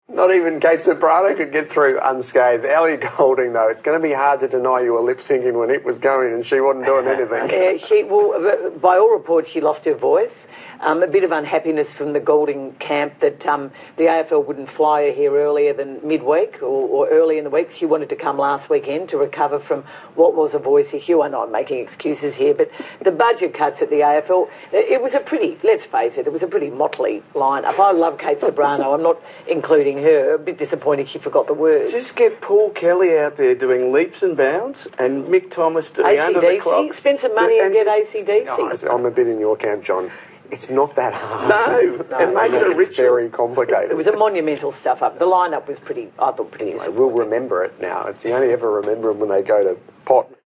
On Offsiders (Oct 4) on ABCTV, I said in a joking tone, but in all seriousness, this: